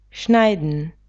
strihat (640x610)stříhat schneiden [šnajdn]